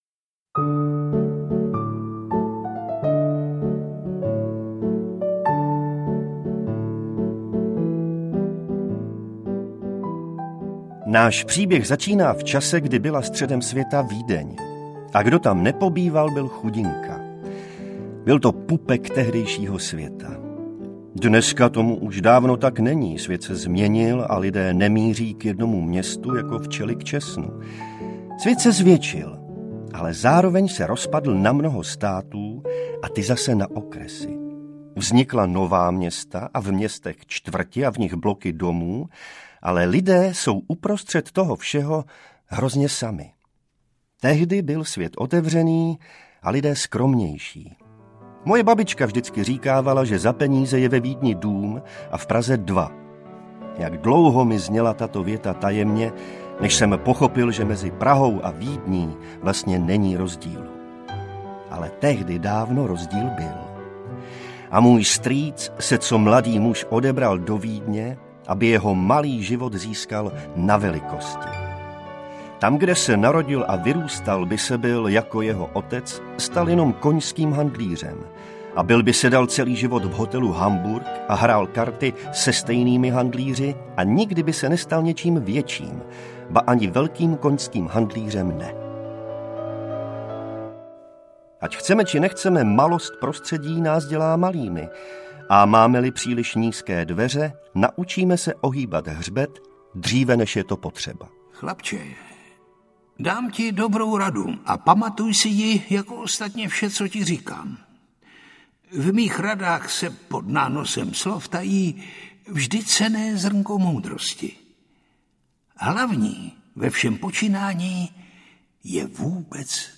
Můj strýc Odysseus audiokniha
Ukázka z knihy
• InterpretJiří Lábus, Miroslav Táborský, Václav Postránecký, Jan Vlasák, Tereza Bebarová, Jaromír Meduna, Arnošt Goldflam, Naďa Konvalinková, Simona Vrbická, Lucie Juřičková